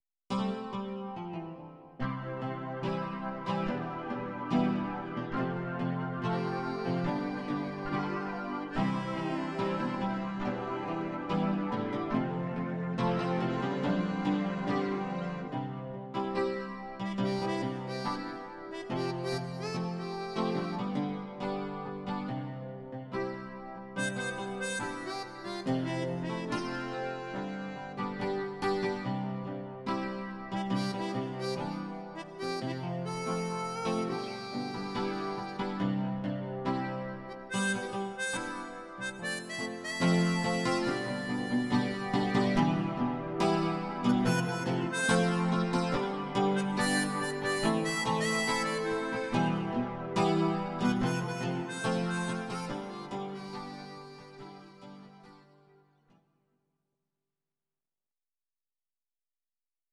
Audio Recordings based on Midi-files
Pop, 1980s